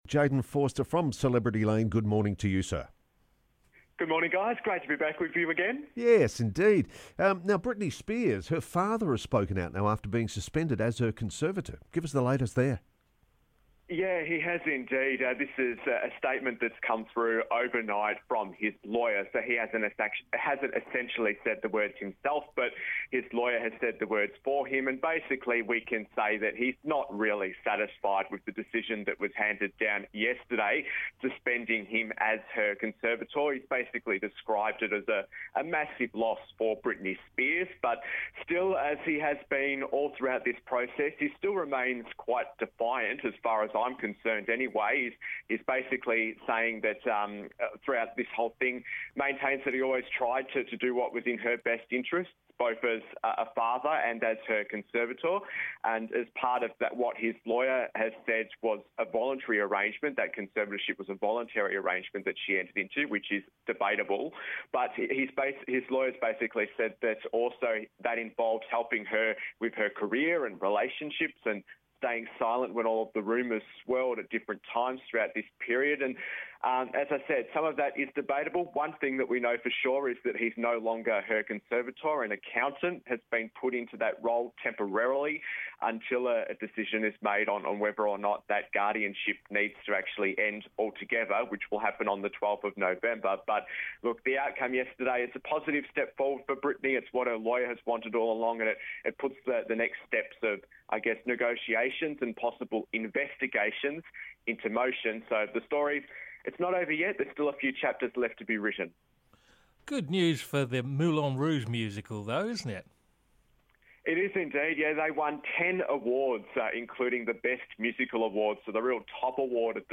entertainment report